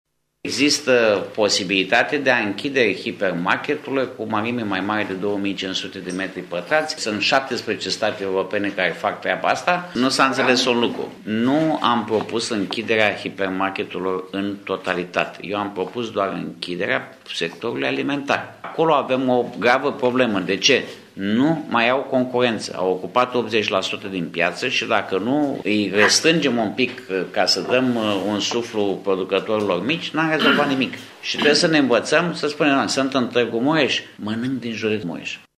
Acesta a explicat, astăzi la Tîrgu-Mureş, că propunerea a vizat doar închiderea sectorului alimentar şi că scopul măsurii a fost încurajarea producătorilor agricoli autohtoni.
Nini Săpunaru s-a aflat la Tîrgu-Mureş pentru a se întâlni cu producătorii şi a discuta problemele pe care aceştia le au.